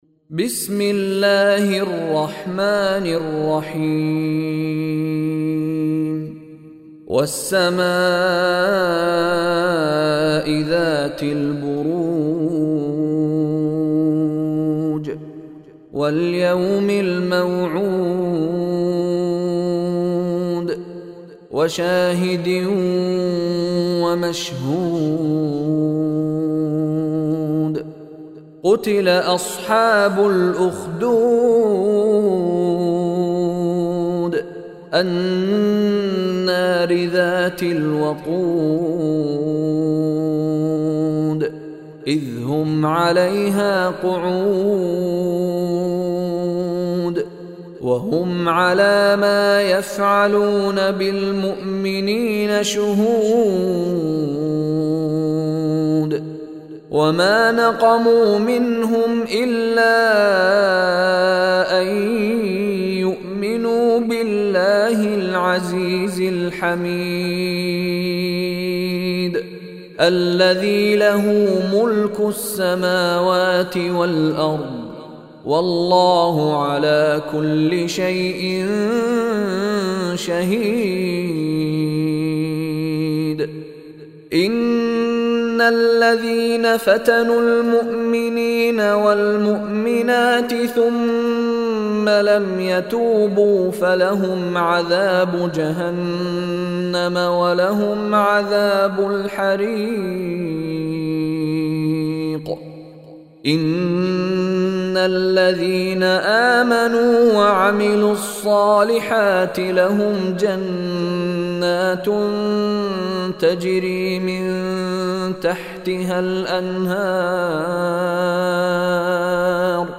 Surah Buruj Recitation by Sheikh Mishary Rashid
Surah Buruj is 85 chapter of Holy Quran. Listen online and download mp3 tilawat / recitation of Surah Buruj in the beautiful voice of Sheikh Mishary Rashid Alafasy.